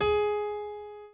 b_pianochord_v100l8-7o5gp.ogg